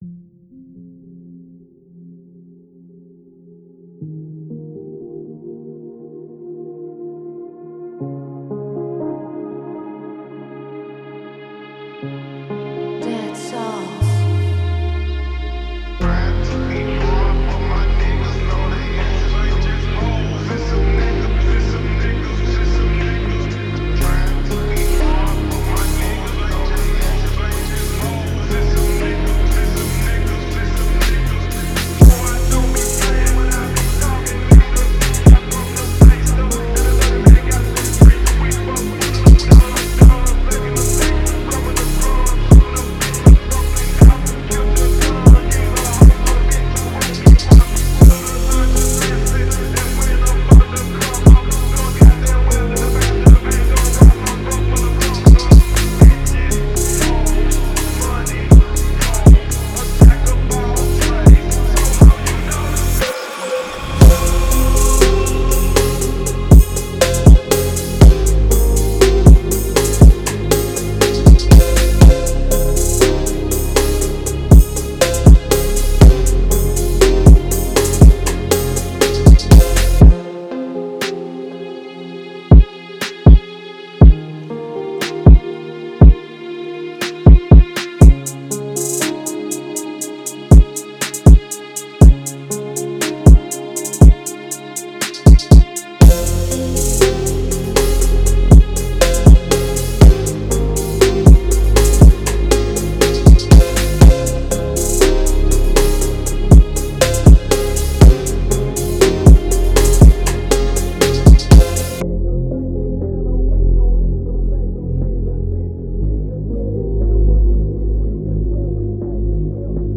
Категория: Фонк музыка